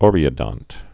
(ôrē-ə-dŏnt)